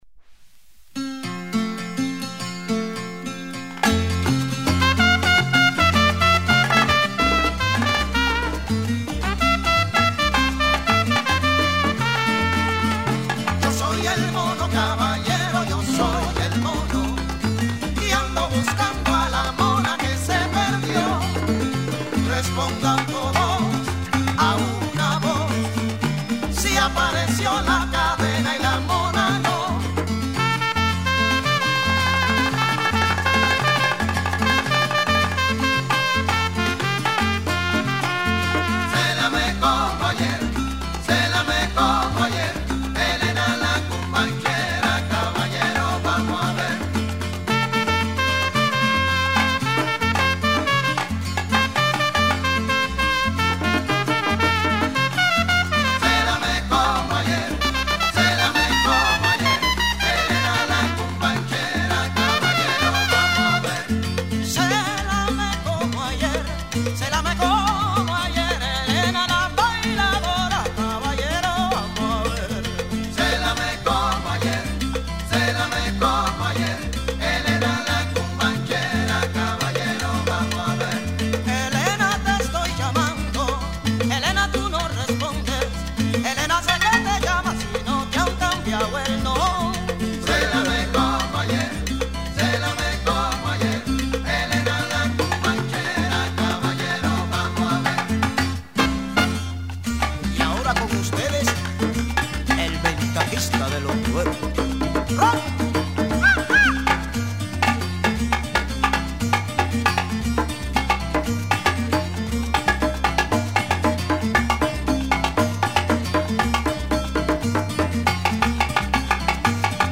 1976年に結成されたキューバのグループ
90'S Cuban Son !!!